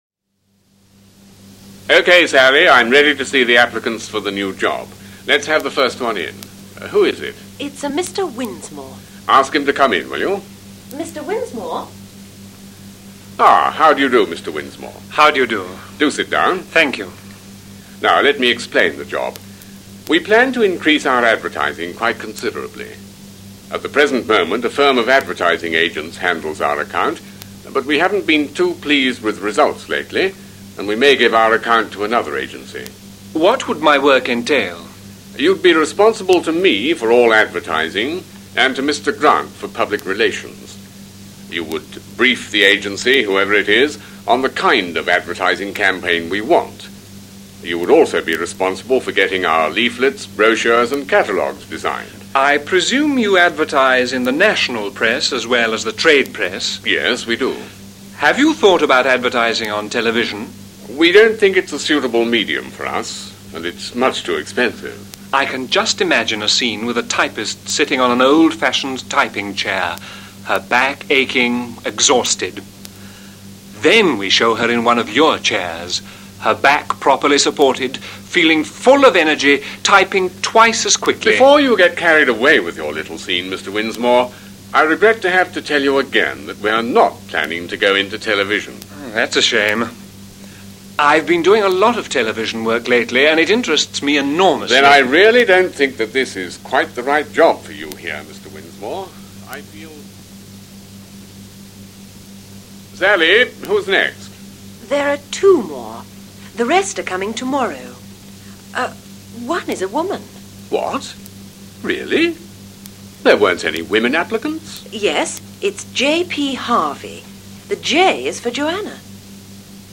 conversation02.mp3